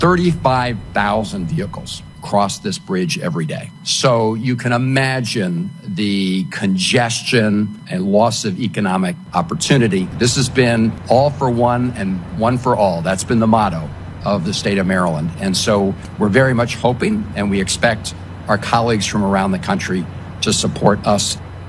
Maryland’s Senator Chris Van Hollen made a plea before a congressional hearing, asking that the funding Biden promised to rebuild the Francis Scott Key Bridge stays in the funding package along with small business loans needed for the state…